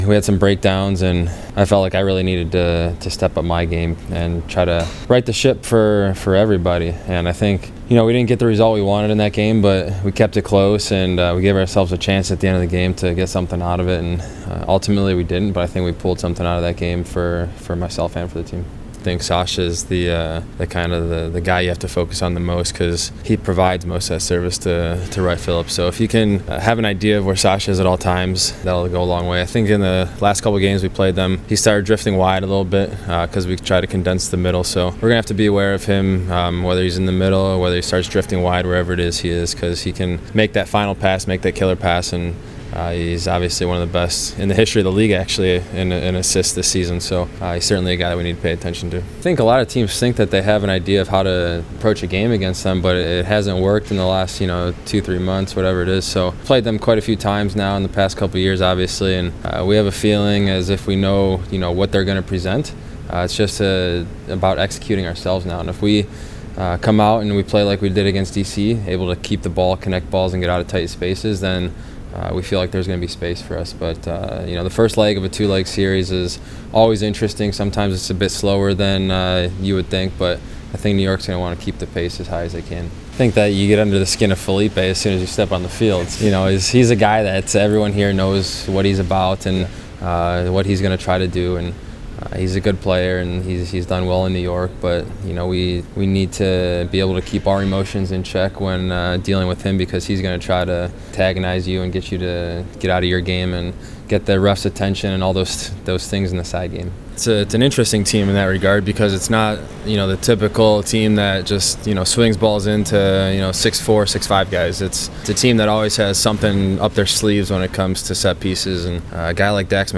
Queste le interviste raccolte prima della rifinitura: